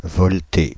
Ääntäminen
Ääntäminen France (Île-de-France): IPA: [vɔl.te] Haettu sana löytyi näillä lähdekielillä: ranska Käännöksiä ei löytynyt valitulle kohdekielelle.